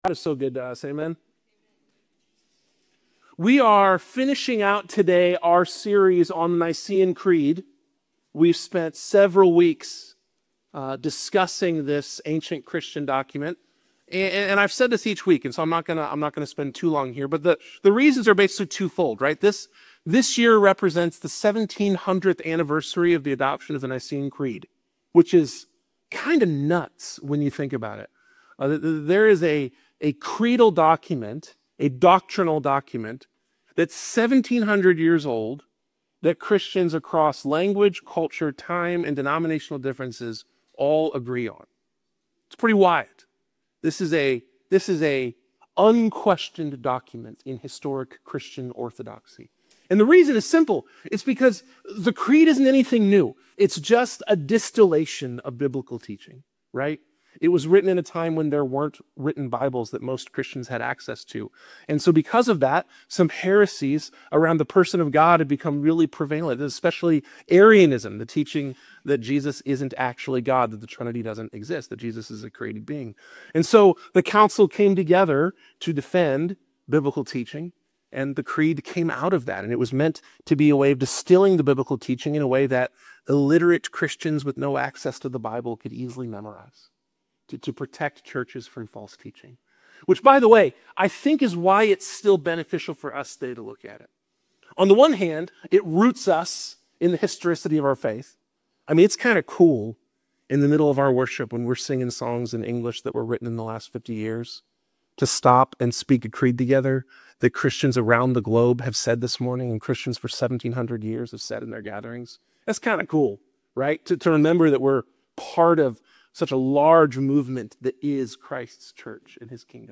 In this concluding sermon of our series on the Nicene Creed, we explore the profound hope found in the resurrection and the return of Jesus Christ. Delve into 1 Thessalonians 4 as we discuss the transformative power of the resurrection, the anticipation of Christ's return, and how these truths impact our daily lives.